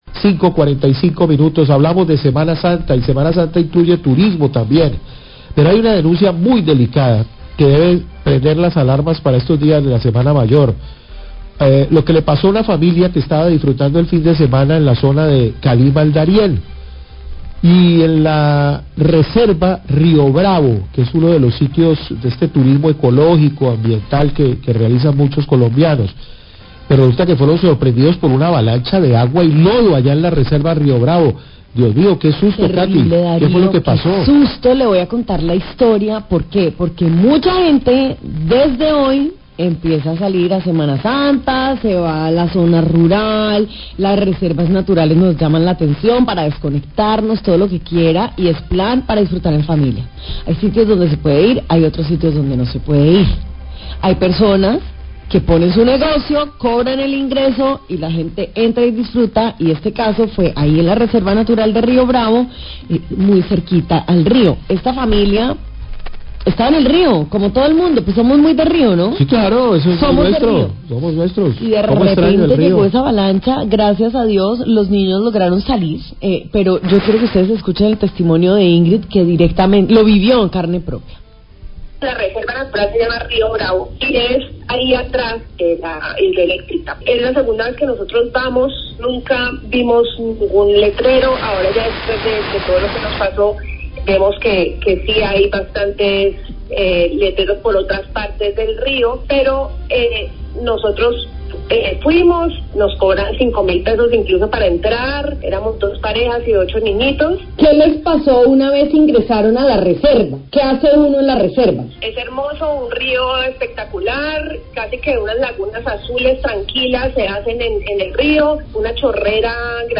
Oyente denuncia creciente subita en Río Bravo generada por apertura de desagüe Lago Calima
Radio